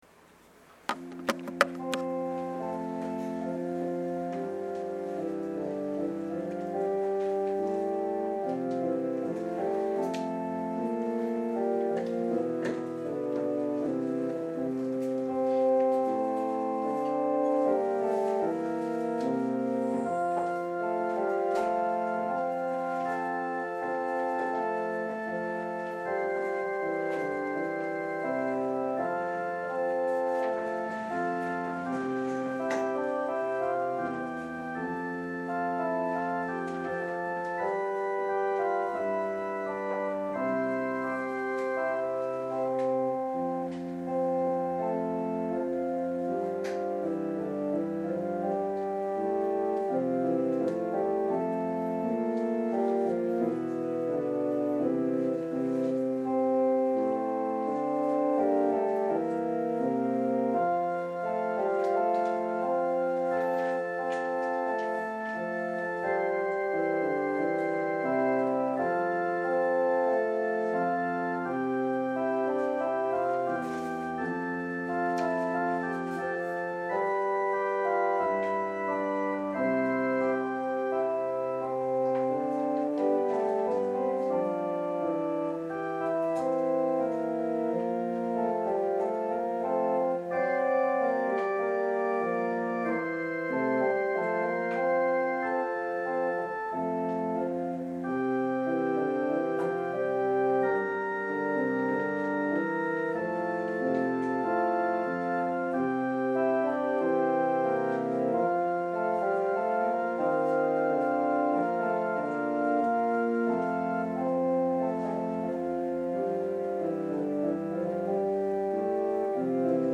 聖日礼拝のご案内（聖霊降臨節第18主日） – 日本基督教団 花小金井教会
2022年10月2日 10:30 世界聖餐日・世界宣教の日 聖餐式 礼拝式次第はこちらをクリック 説教「主の山に、備えあり」 創世記２２章１～１４節